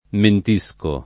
mentire v.; mentisco [